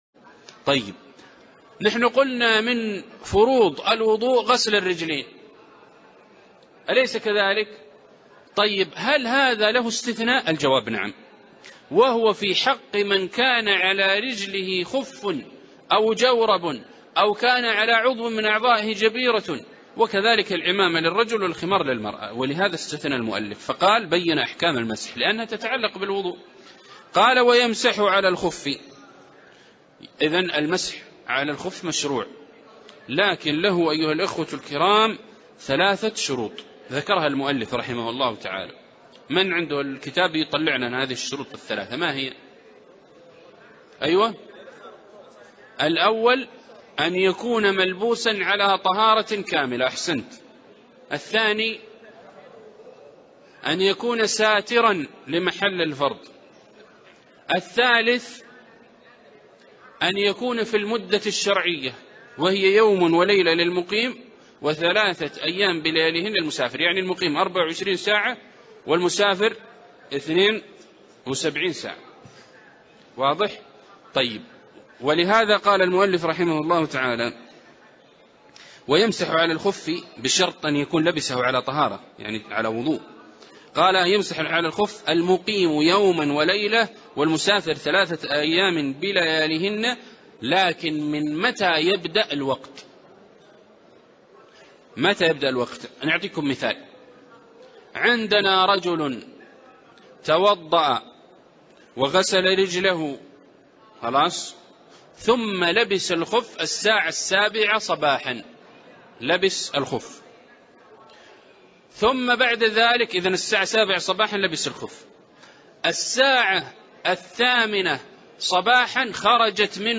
عنوان المادة الدرس ( 6) شرح فروع الفقه لابن المبرد